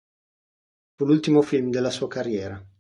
Pronounced as (IPA) /karˈrjɛ.ra/